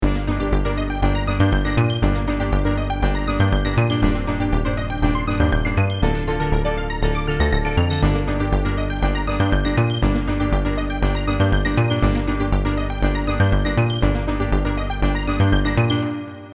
Leaving the first two bars as they are, we turn the chord control for bar three a quarter of the way and then take the Function slider for bar four to Dominant (V). This creates a nice transition at the end of the first four bars.